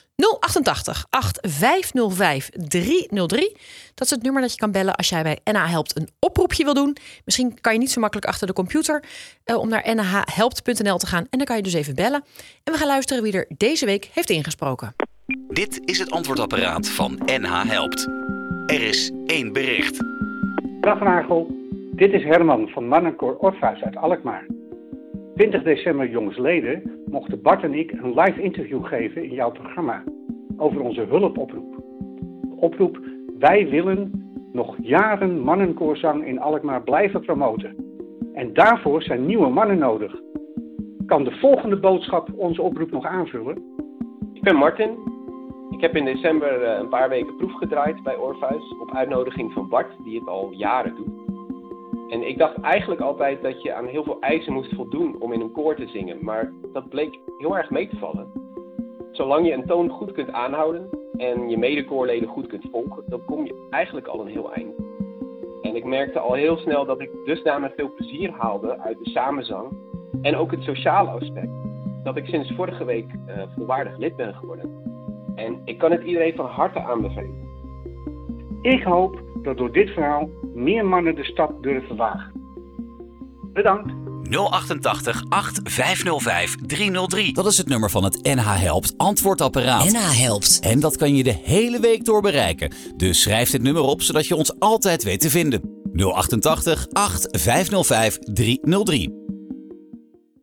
Ja, dan bel ik' En met de telefoon op speaker deden we elk ons ding.
Het ingesproken antwoordapparaat.